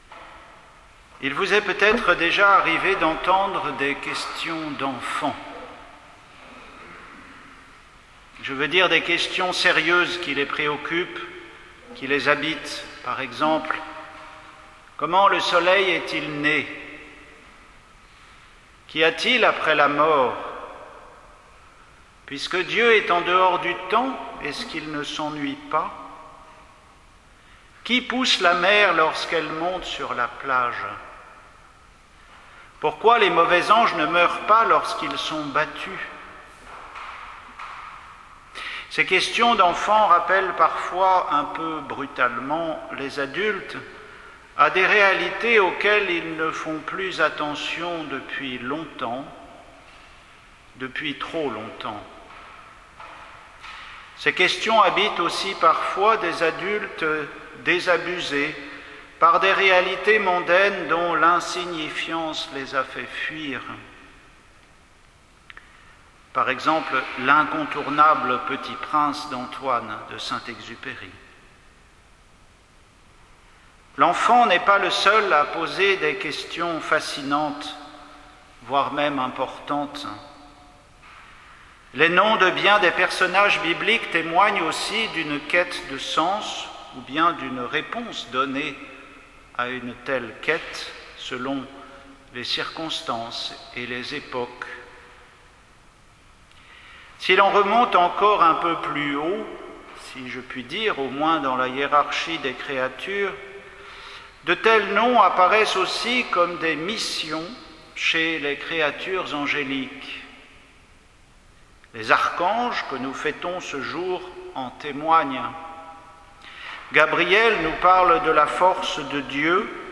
Homélie pour la solennité de Saint Michel, prononcée en l'abbaye St Michel de Kergonan